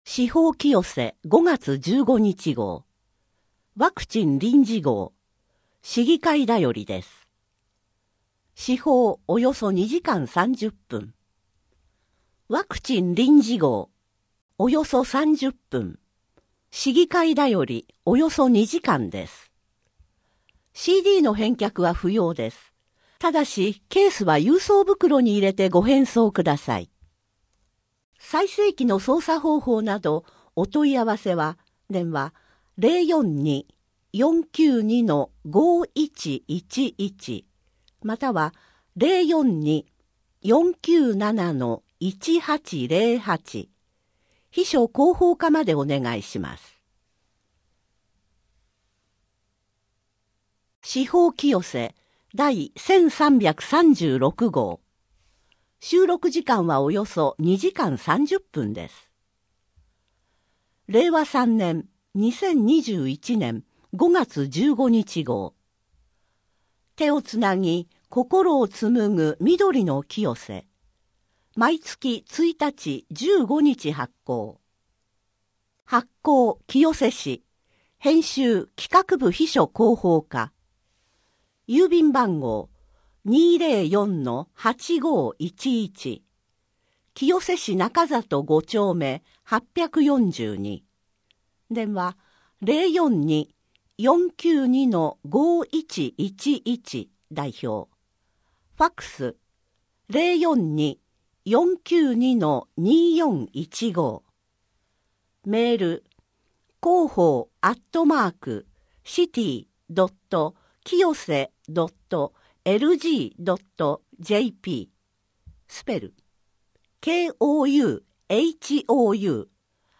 声の広報 声の広報は清瀬市公共刊行物音訳機関が制作しています。